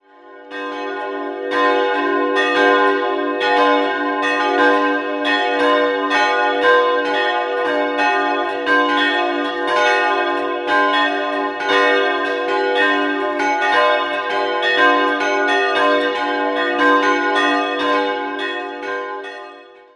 Das kleine Filialkirchlein wurde 1709 auf Basis einer Kapelle erbaut und im Jahr 1714 geweiht, wobei der Turmunterbau noch aus der Zeit der Frühgotik stammt. 3-stimmiges Geläute: h'-d''-f'' Alle Glocken wurden 1921 vom Bochumer Verein für Gussstahlfabrikation gegossen.